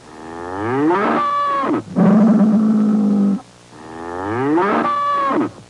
Bellowing Bull Sound Effect
Download a high-quality bellowing bull sound effect.
bellowing-bull.mp3